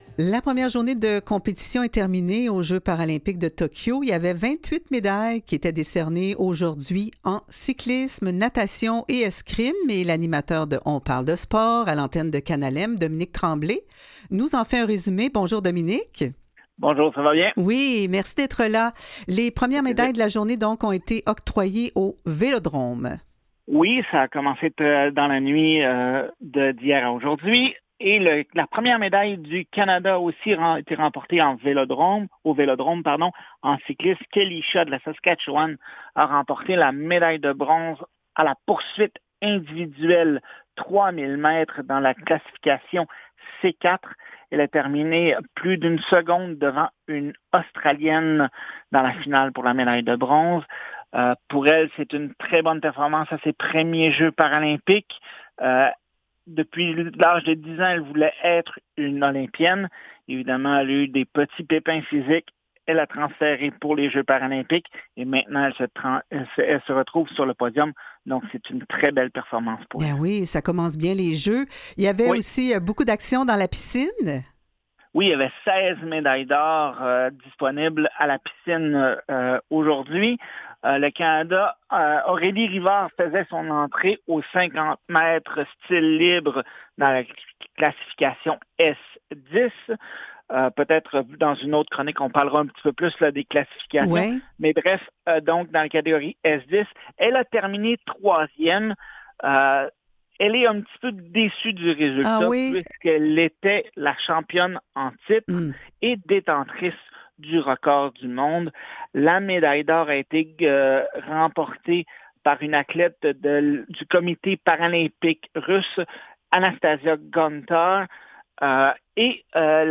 Aux Quotidiens Revue de presse et entrevues du 25 août 2021